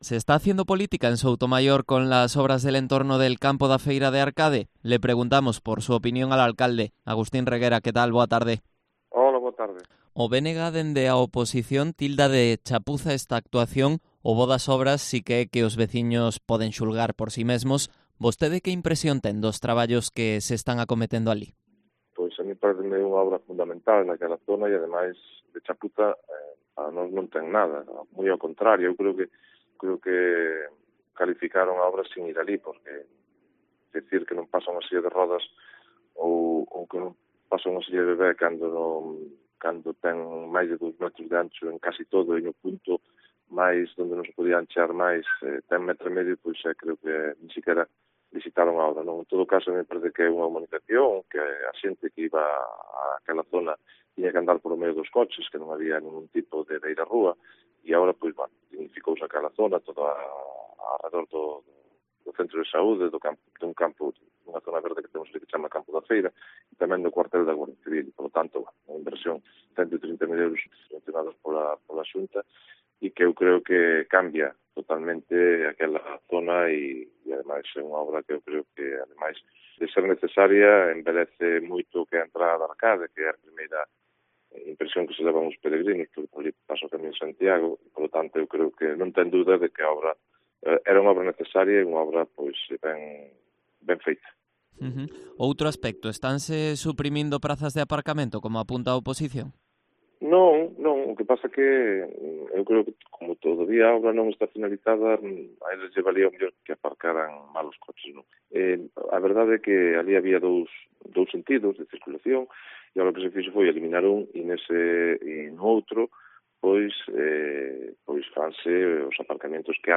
Entrevista a Agustín Reguera, alcalde de Soutomaior